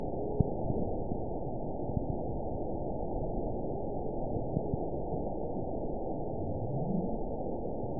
event 921915 date 12/22/24 time 18:59:42 GMT (4 months, 2 weeks ago) score 9.53 location TSS-AB04 detected by nrw target species NRW annotations +NRW Spectrogram: Frequency (kHz) vs. Time (s) audio not available .wav